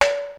Percussive FX 08 ZG